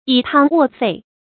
以湯沃沸 注音： ㄧˇ ㄊㄤ ㄨㄛˋ ㄈㄟˋ 讀音讀法： 意思解釋： 見「以湯止沸」。